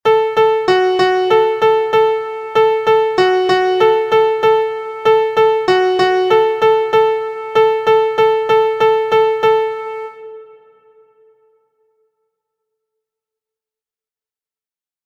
• Origin: USA – Traditional
• Key: D Major
• Time: 2/4
• Form: rhythm: AAAA – pitches: AAAb
• Pitches: beginners: Mi So